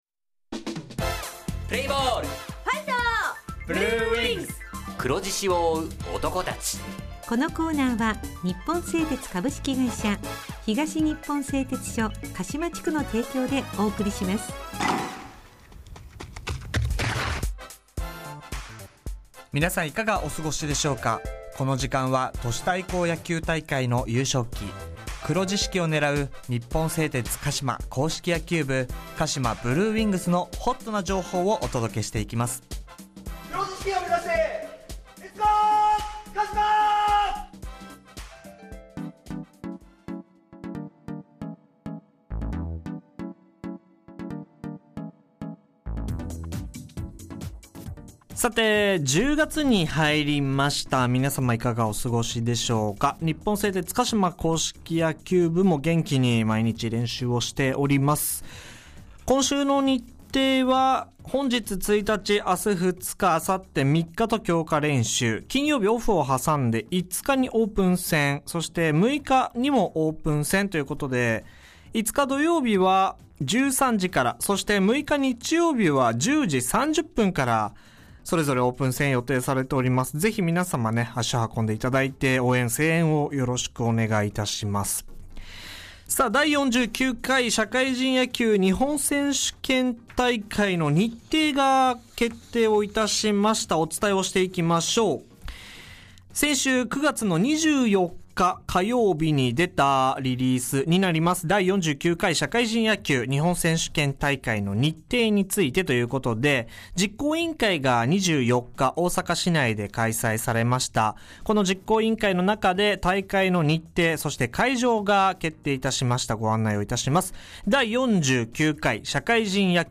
インタビュー / 社会人野球日本選手権大会について
地元ＦＭ放送局「エフエムかしま」にて鹿島硬式野球部の番組放送しています。